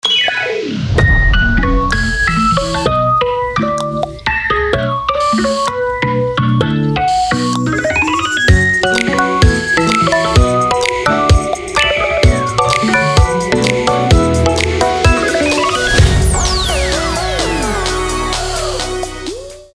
позитивные
веселые
без слов
инструментальные